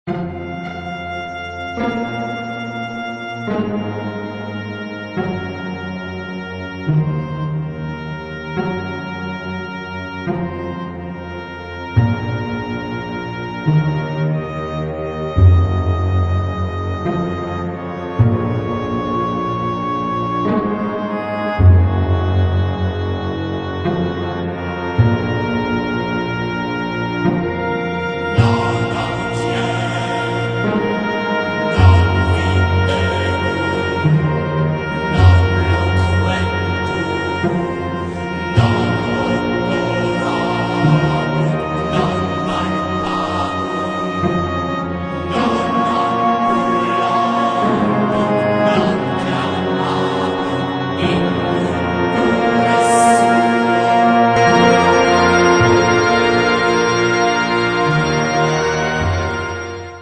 Sombres, incantatoires et mécaniques
un épilogue funèbre